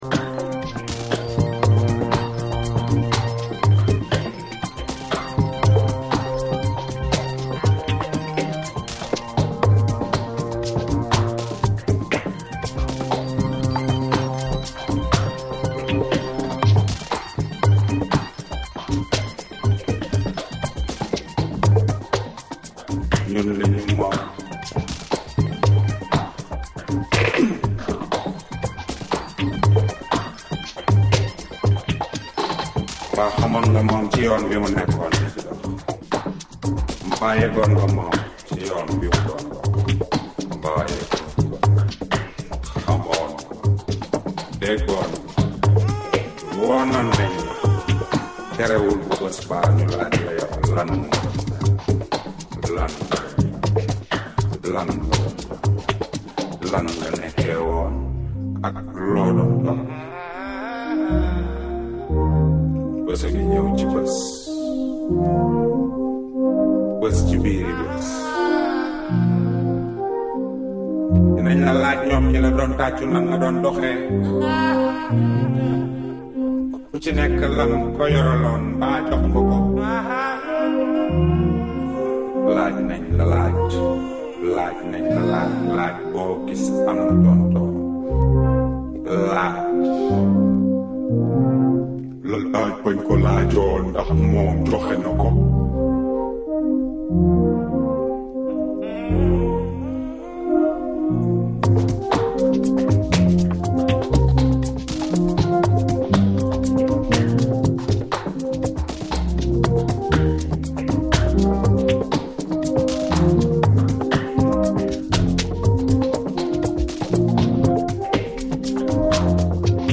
captivating tones of Senegalese vocalist